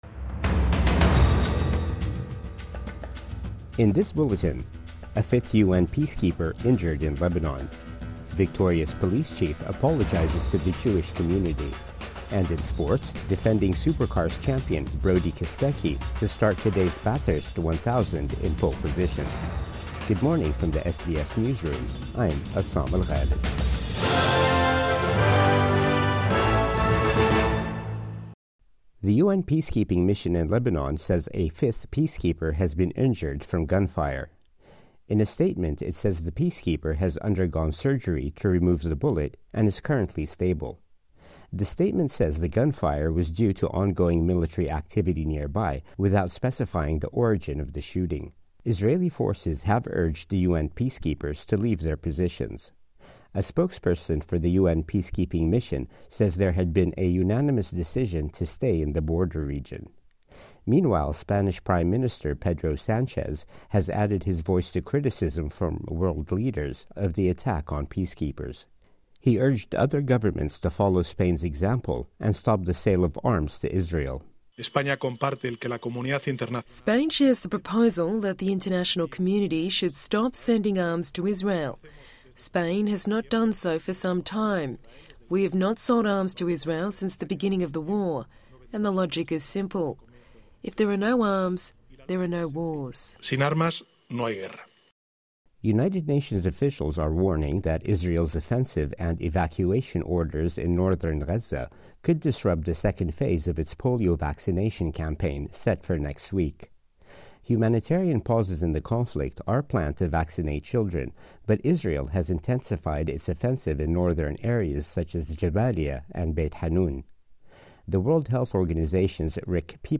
Morning News Bulletin 13 October 2024